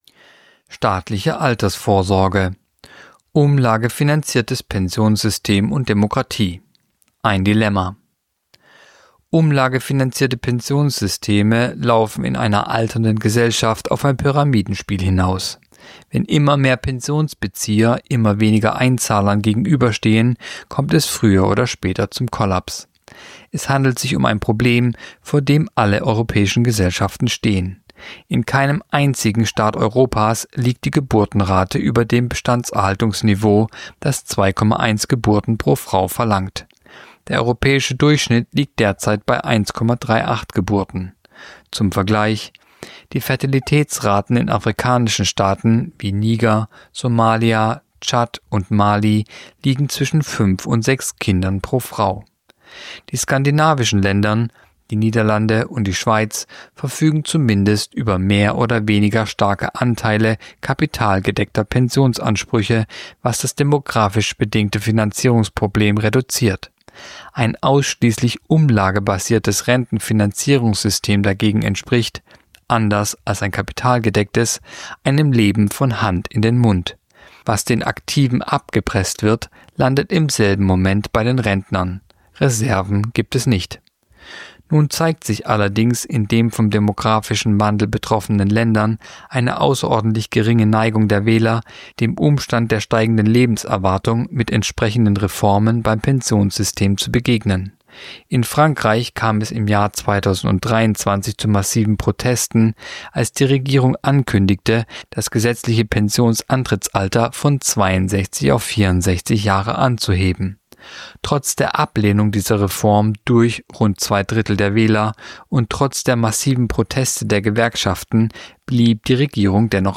Kolumne der Woche (Radio)Umlagefinanziertes Pensionssystem und Demokratie